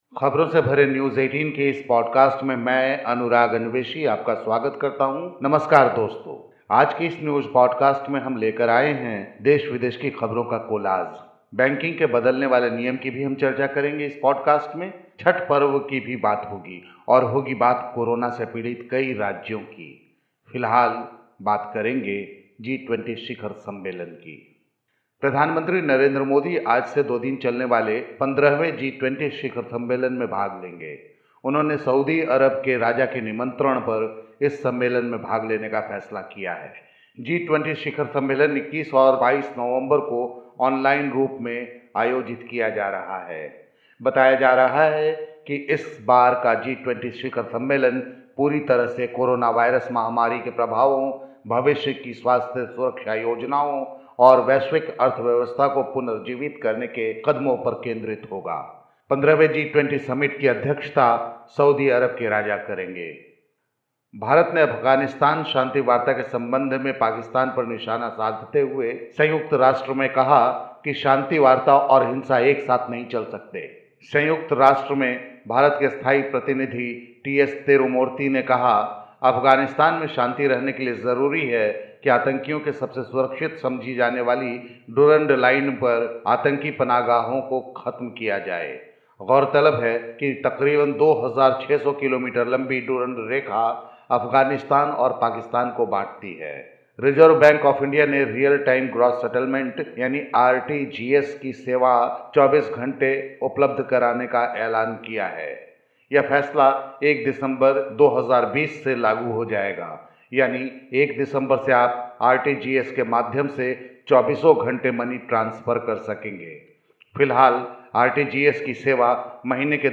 आज के इस न्यूज पॉडकास्ट में हम लेकर आए हैं देश-विदेश की खबरों का कोलाज. बैंकिंग के बदलने वाले नियम की भी चर्चा करेंगे हम इस पॉडकास्ट में.